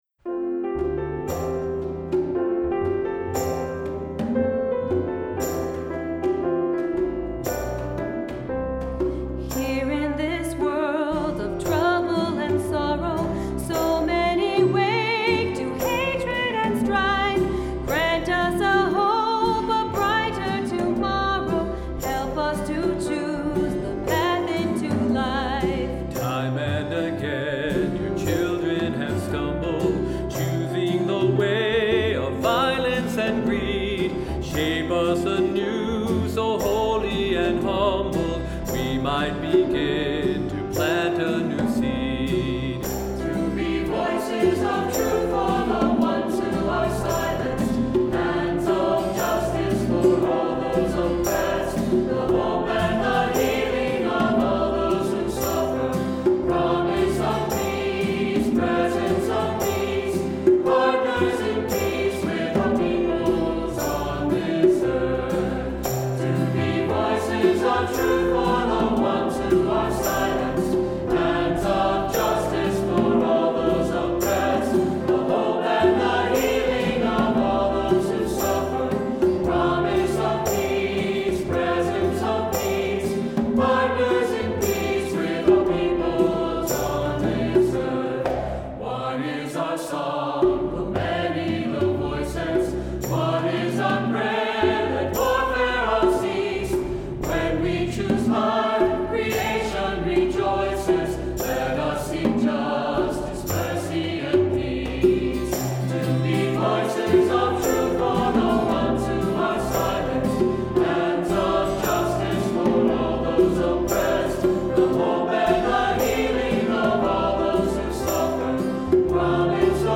Accompaniment:      Keyboard
Music Category:      Christian
For cantor or soloist